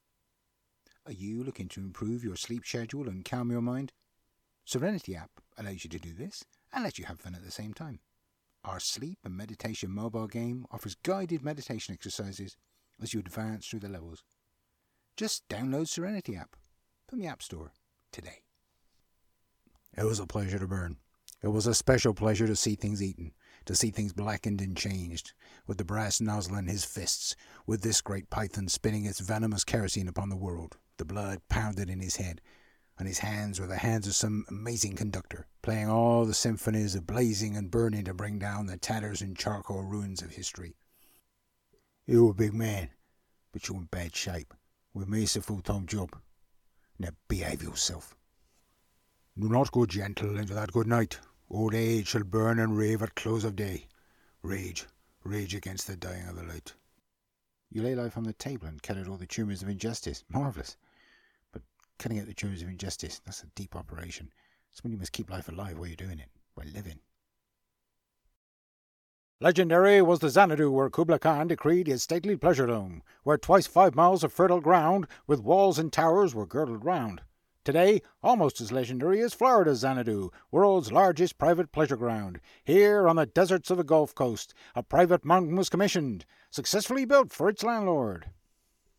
Sample Voice Reel